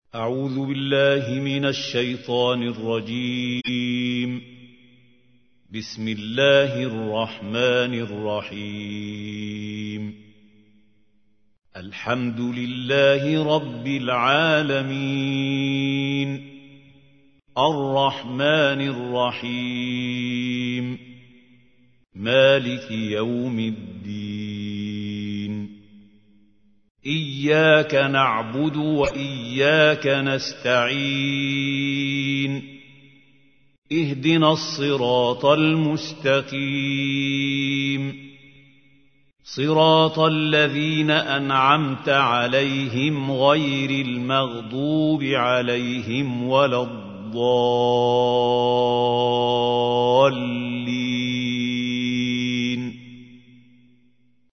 تحميل : 1. سورة الفاتحة / القارئ محمود خليل الحصري / القرآن الكريم / موقع يا حسين